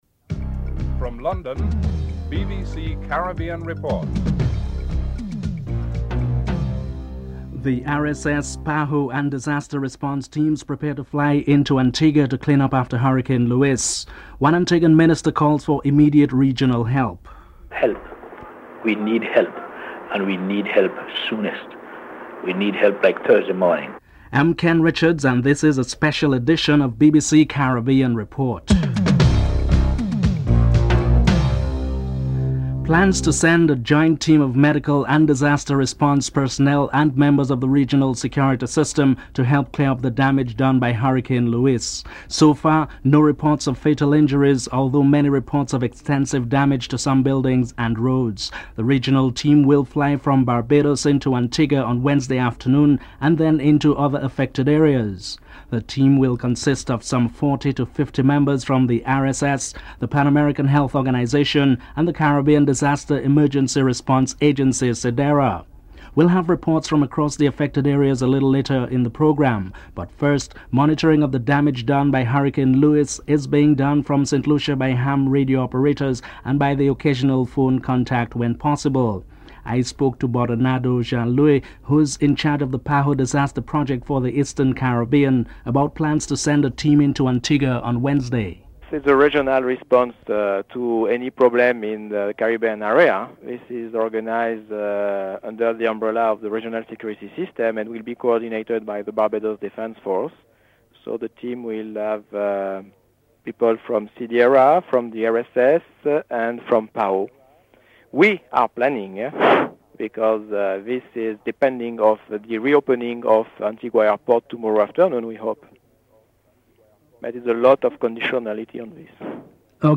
Antigua and Barbuda's Aviation Minister Robin Yearwood expresses concern about the storm and appeals to his CARICOM colleagues for immediate assistance.
6. Antigua's Aviation Minister expresses his concerns about the hurricane and calls for immediate regional help (10:55-12:14)